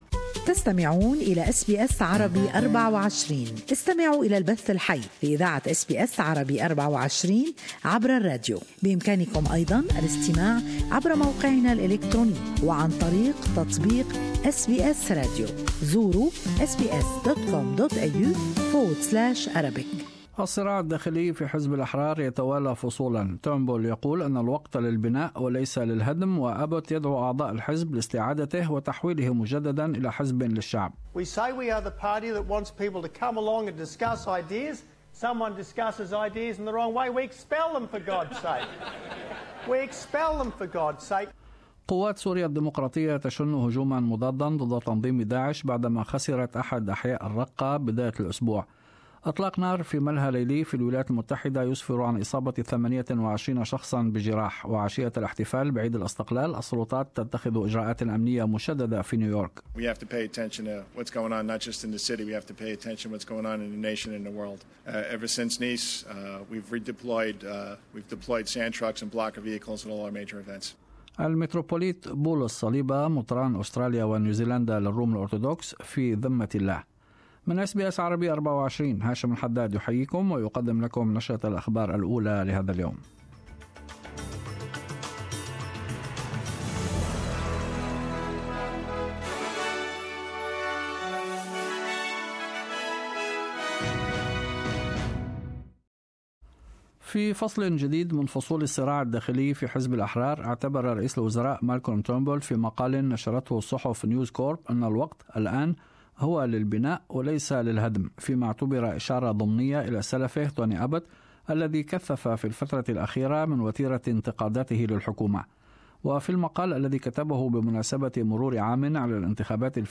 نشرة الأخبار:فصل جديد من فصول الصراع الداخلي في حزب الاحرار